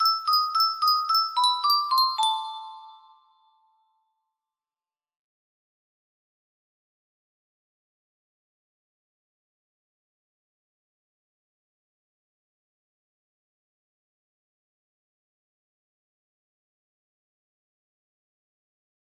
yo music box melody
Full range 60